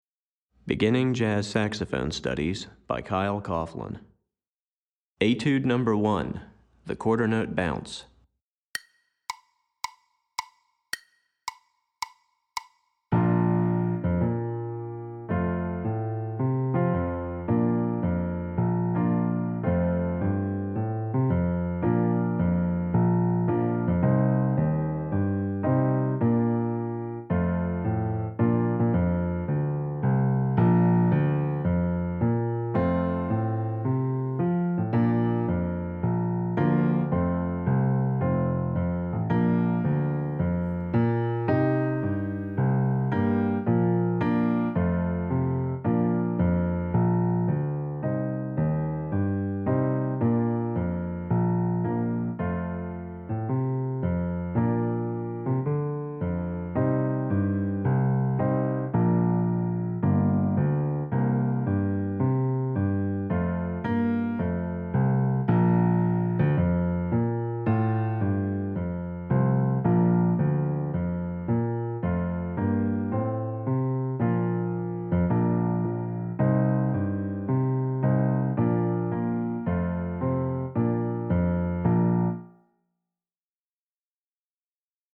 piano
Piano Accompaniment